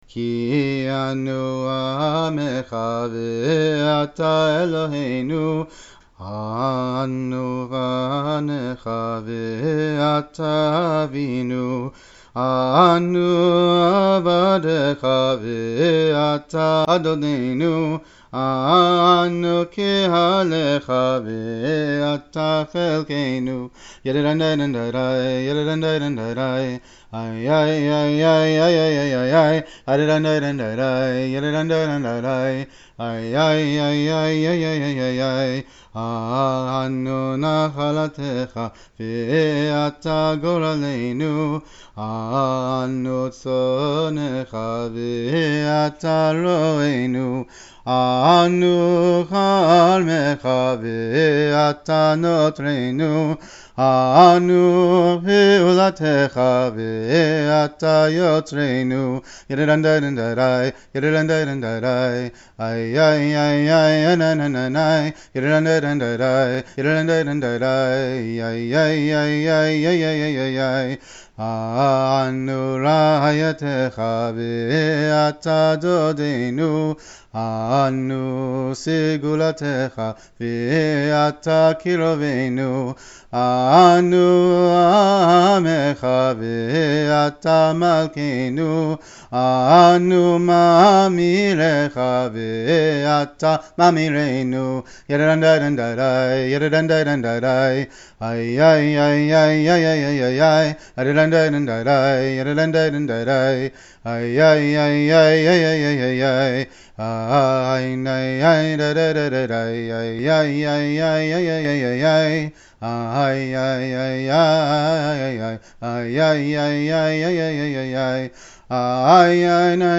So is this Chabad tune.